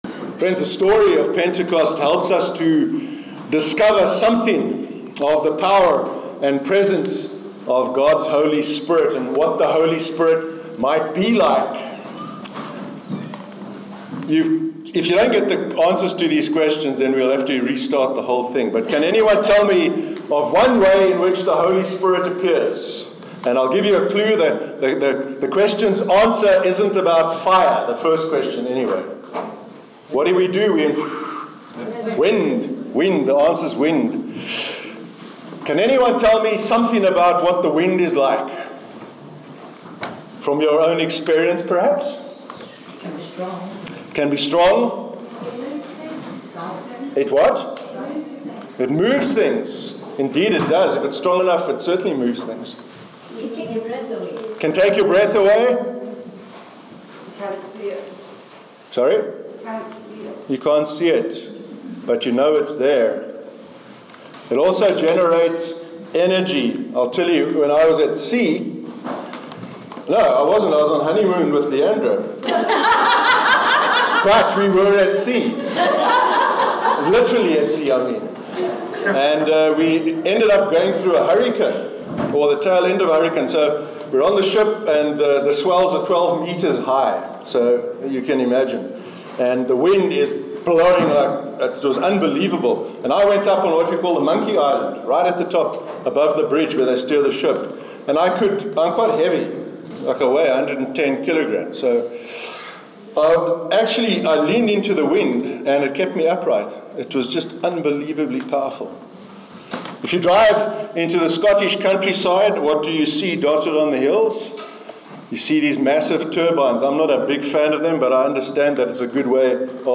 pentecost_message_4th_june_2.mp3